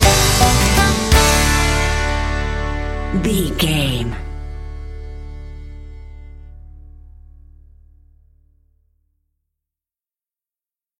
Ionian/Major
D
drums
electric piano
electric guitar
bass guitar
banjo